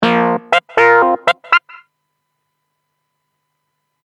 | kangaroo arpeggio |